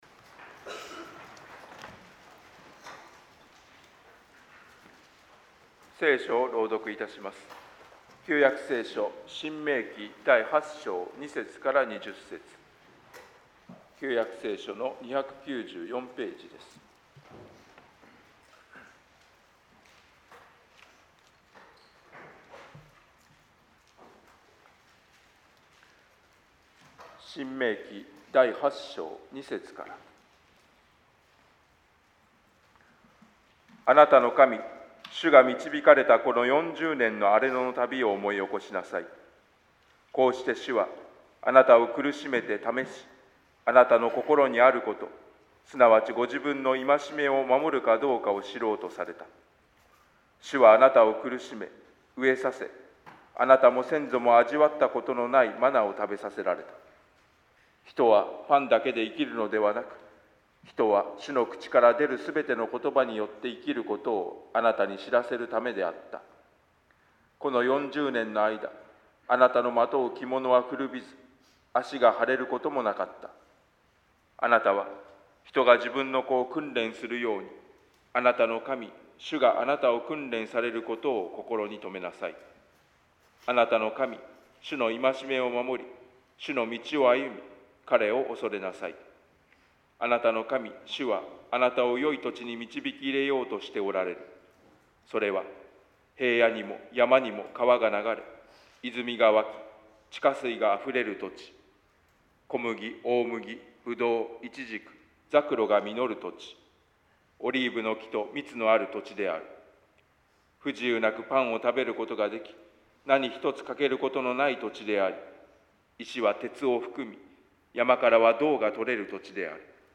説教題「思い起こす信仰」